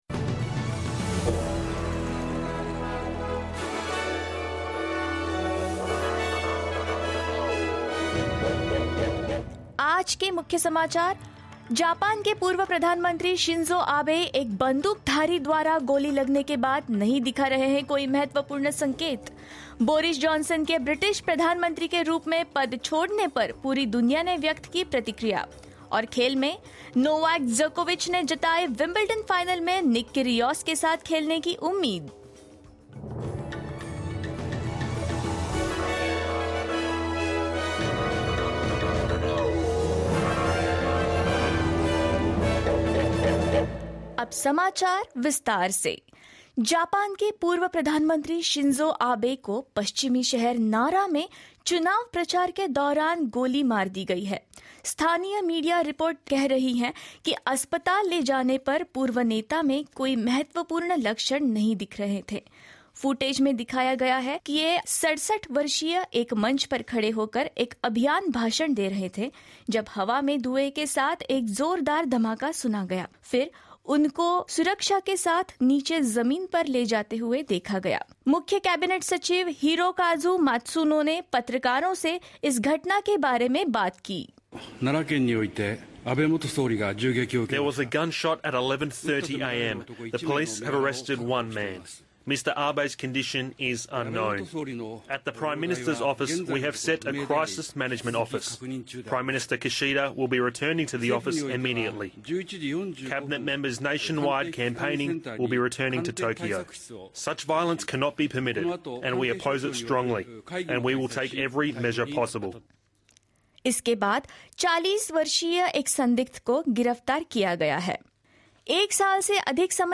In this latest SBS Hindi bulletin: Former Japanese Prime Minister Shinzo Abe critical after being gunshot; Leaders around the world reacts to Boris Johnson's resignation as British Prime Minister; Novak Djokovic hopeful for the chance to meet Nik Kyrgios in the Wimbledon final and more